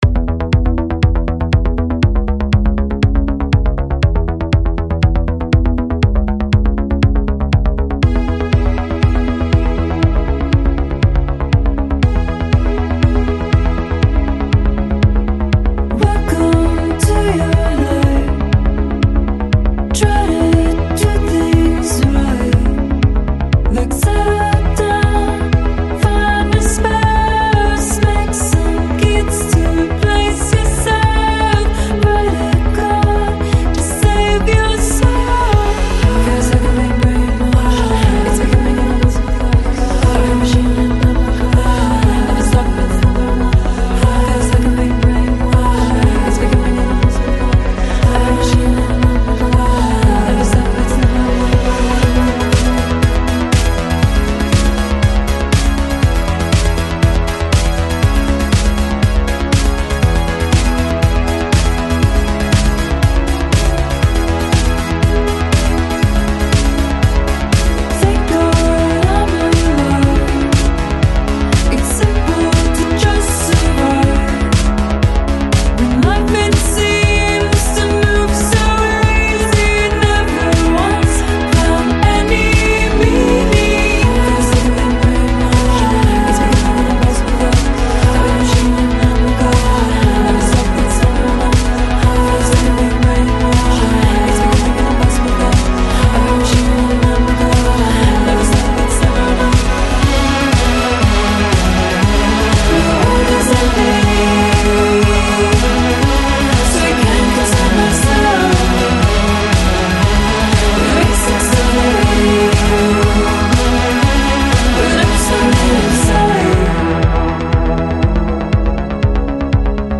Synthpop, Technopop